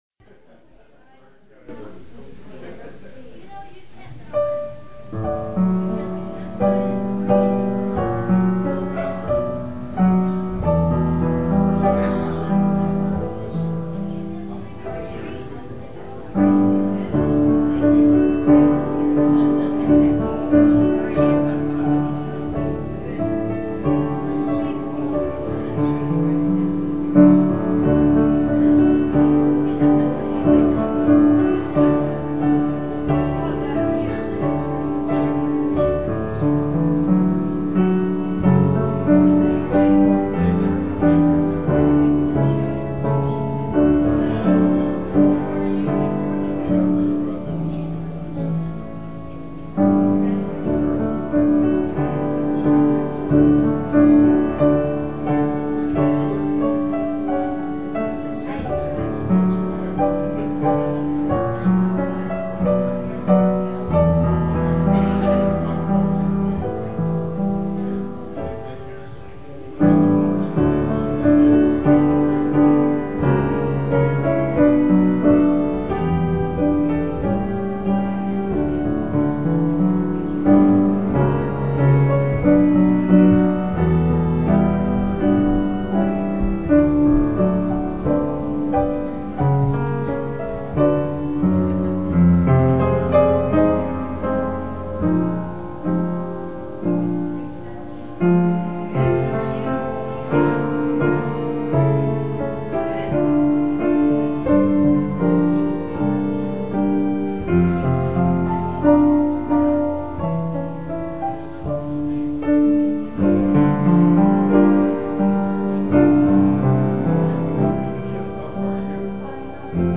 piano and organ.